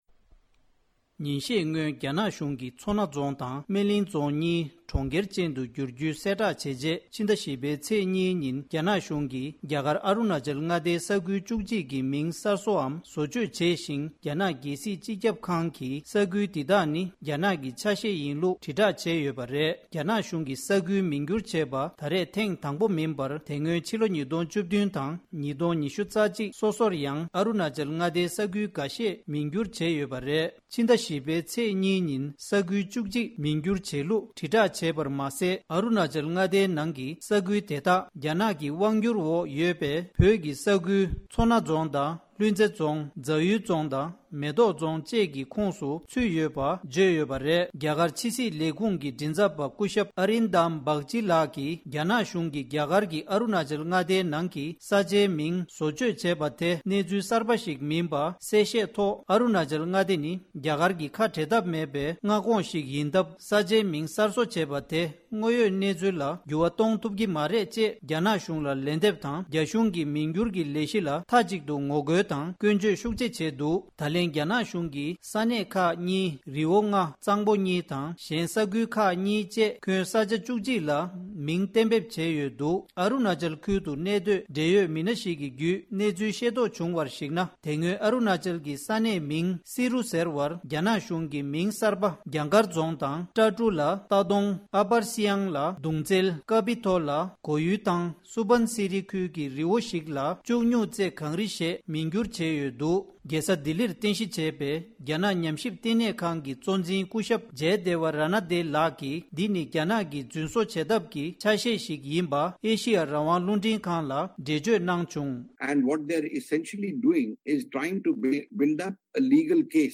སྒྲ་ལྡན་གསར་འགྱུར།
བོད་གནས་ཉམས་ཞིབ་པར་བཀའ་འདྲི་ཞུས་པ་ཞིག་ལ་གསན་རོགས་ཞུ།